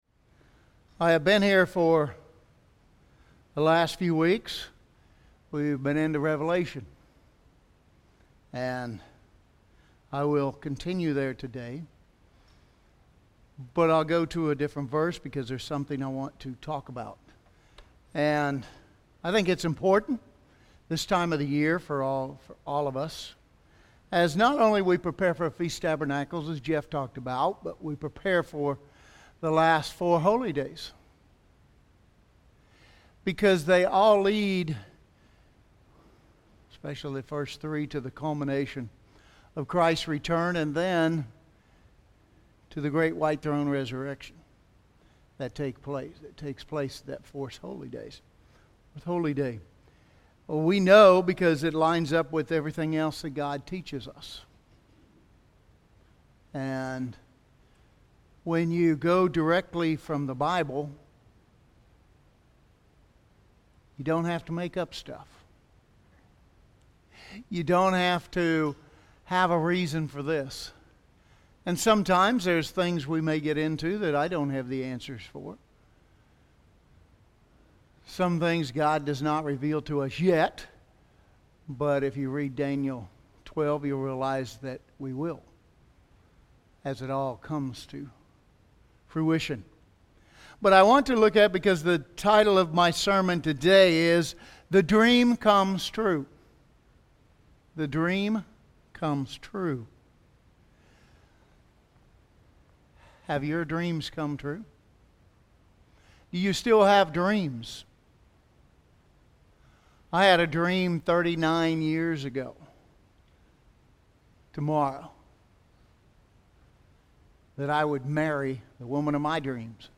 But I want to look at it because the title of my sermon today is The Dream Comes True.